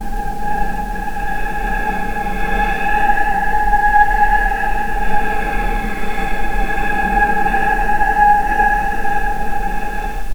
healing-soundscapes/Sound Banks/HSS_OP_Pack/Strings/cello/sul-ponticello/vc_sp-G#5-pp.AIF at 01ef1558cb71fd5ac0c09b723e26d76a8e1b755c
vc_sp-G#5-pp.AIF